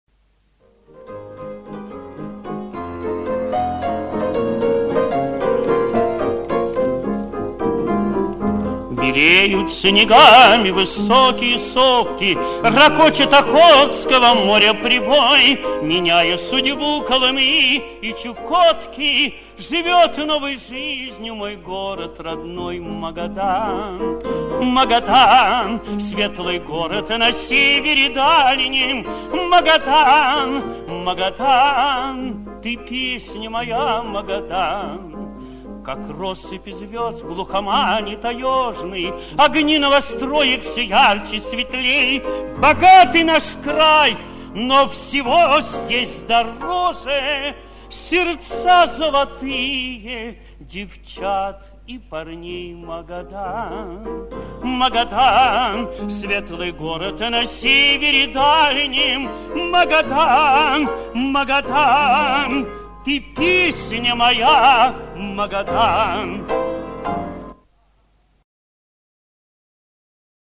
Песня
в исполнении автора